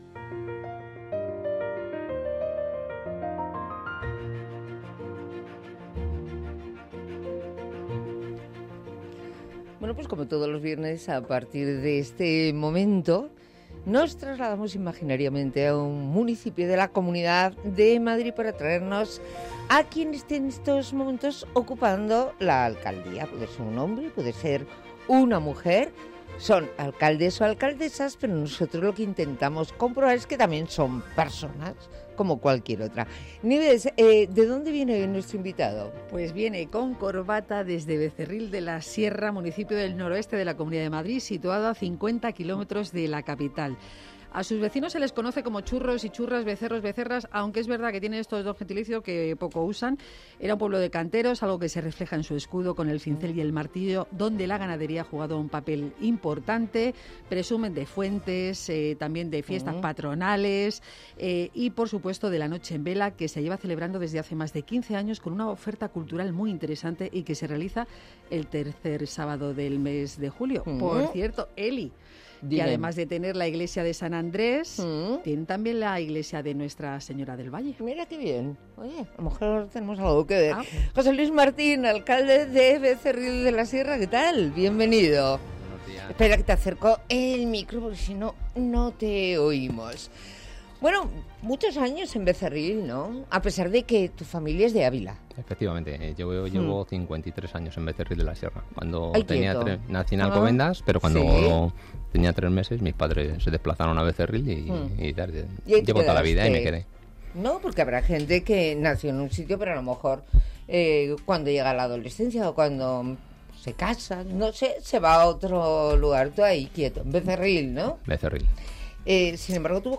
En la sección de Los alcaldes también son humanos de Onda Madrid, ha venido a demostrarlo el regidor de Becerril de la Sierra.